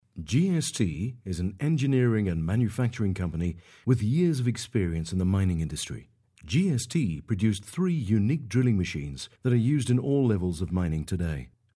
Audio Visual Voice Over